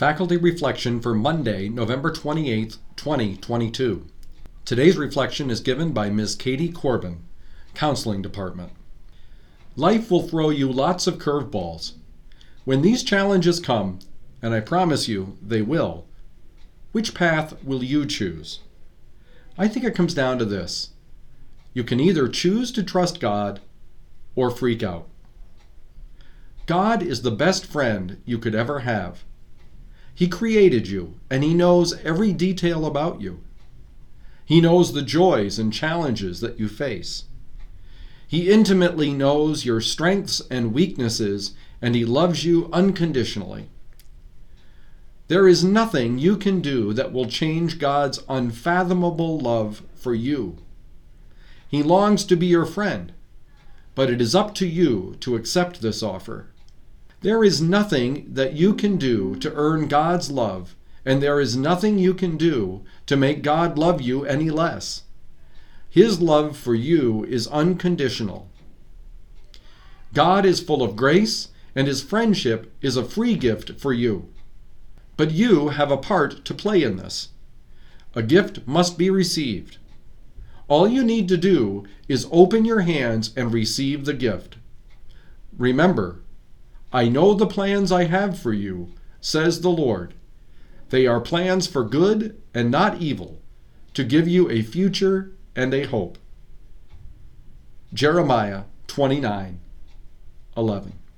Advent reflection